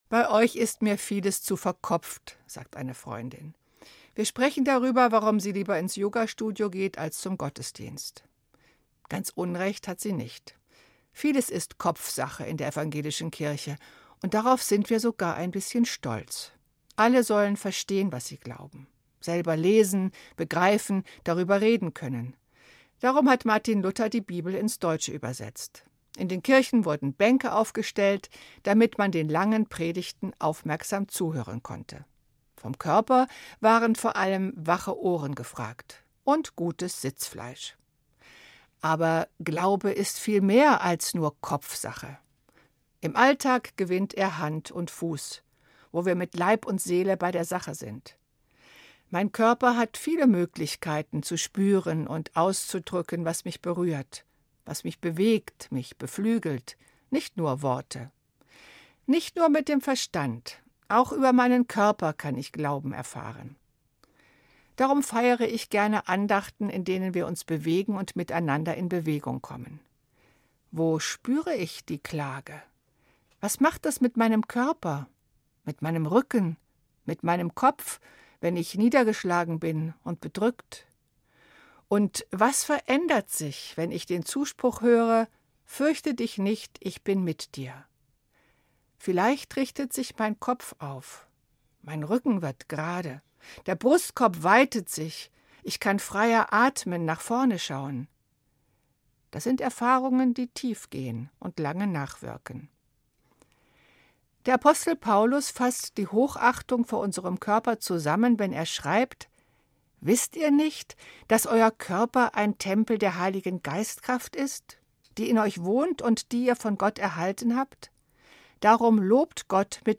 Evangelische Pfarrerin, Marburg